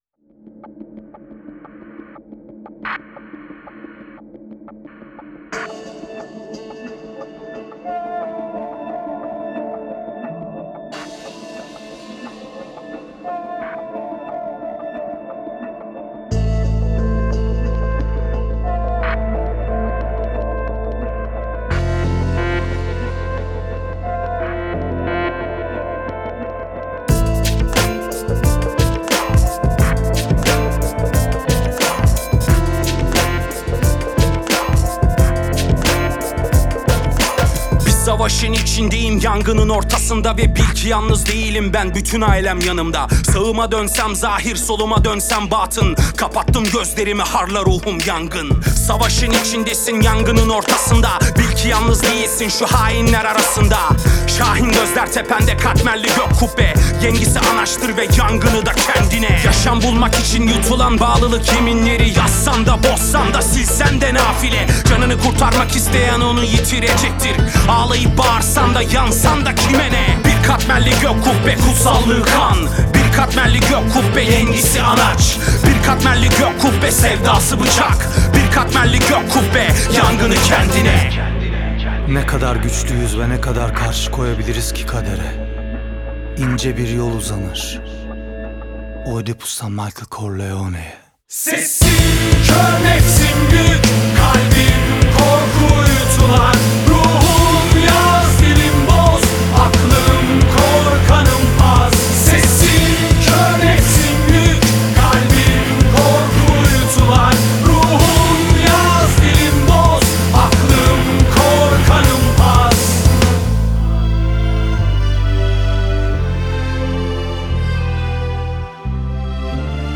heyecan gerilim aksiyon şarkı.